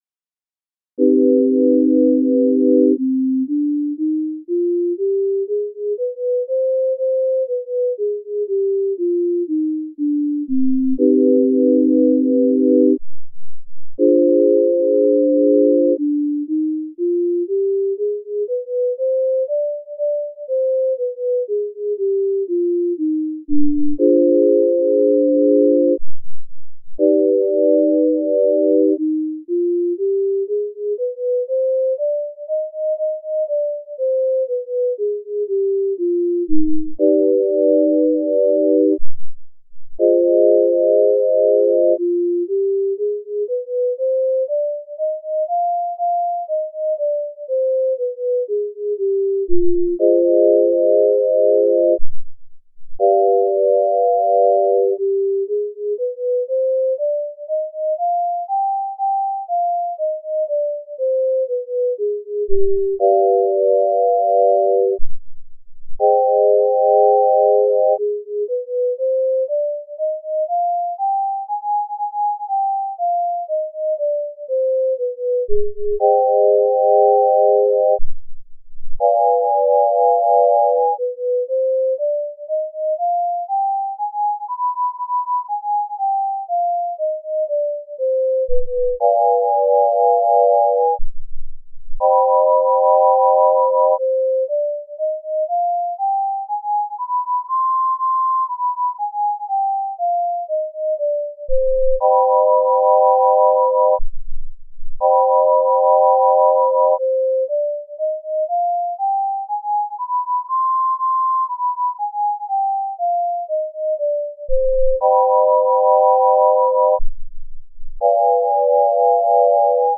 C-Harmonic Minor Scale - Left Ear Tempered - Right Ear Pythagorean